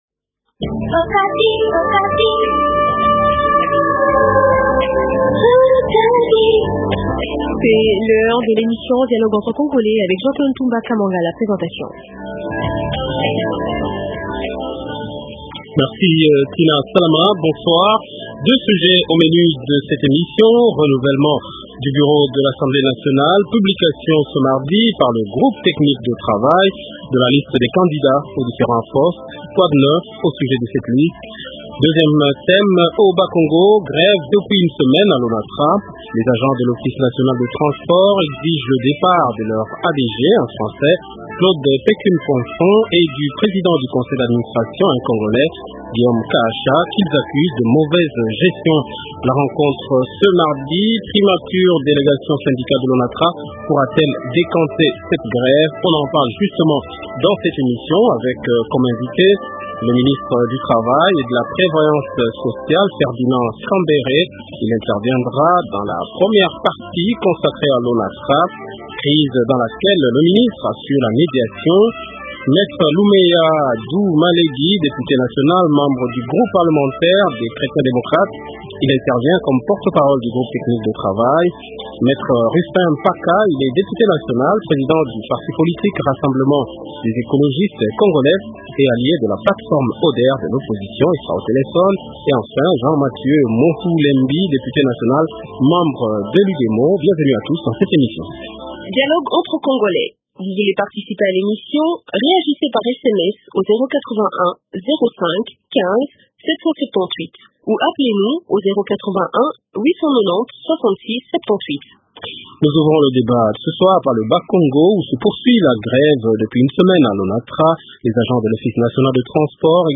Deux thèmes au débat de ce soir :